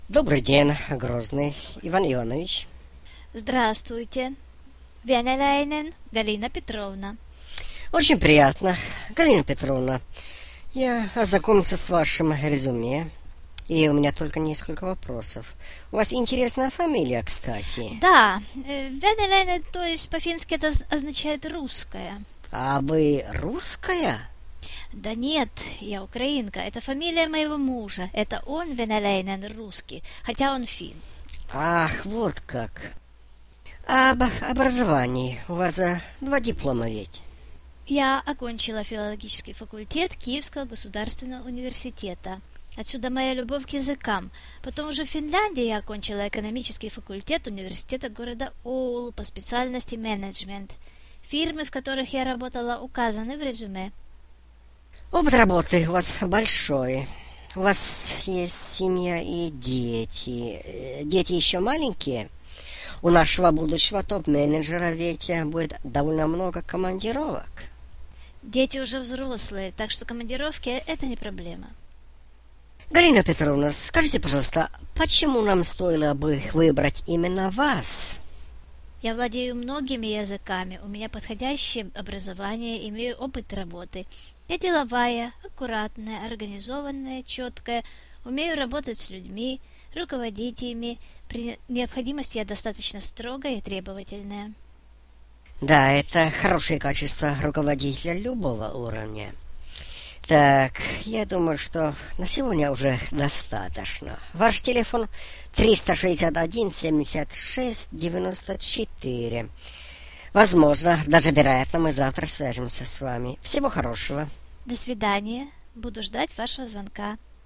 4. Интервью с третьим кандидатом.